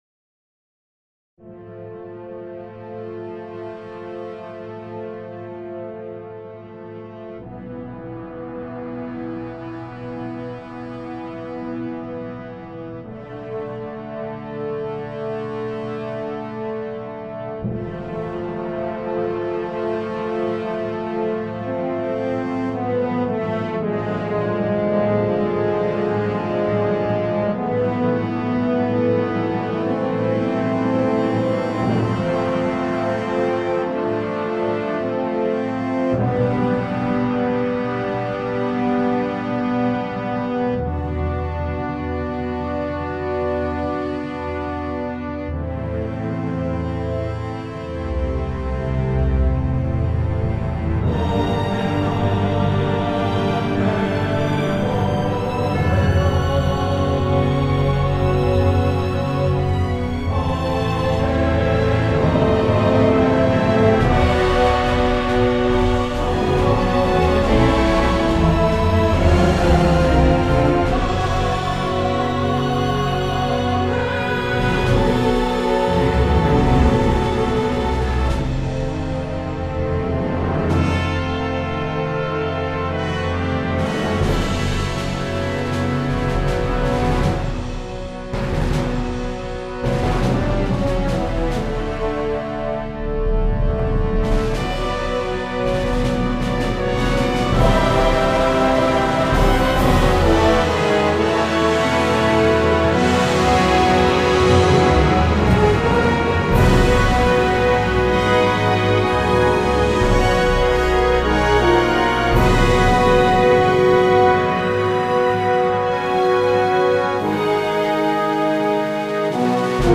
آهنگ ارکسترال